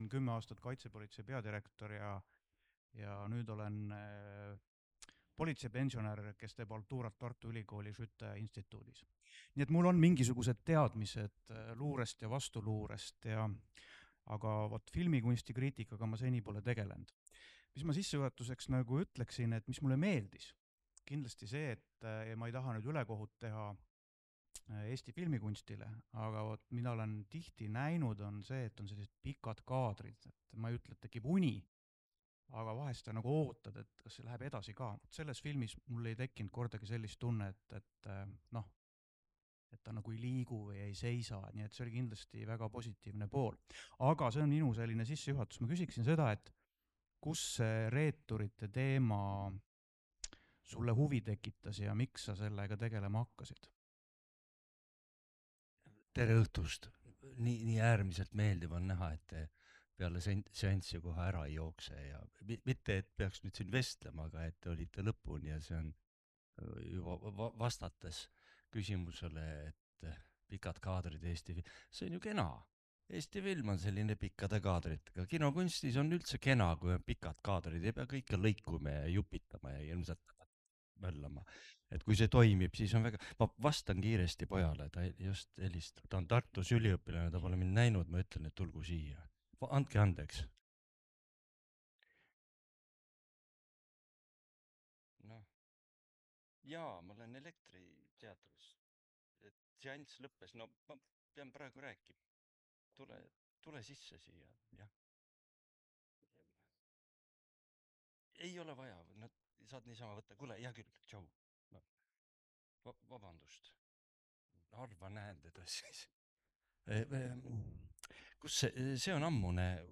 29. septembri 2025 õhtul täitus Elektriteatri saal filmihuvilistega, kes olid tulnud vaatama spioonipõnevikku/peredraamat “Ühemõõtmeline mees”. Pärast filmi vestles režissööri ja stsenaristi Andres Puustusmaaga endine Kaitsepolitsei direktor Arnold Sinisalu. Kaasahaarav vestlus kulges nii olmelistel kui filosoofilistel radadel.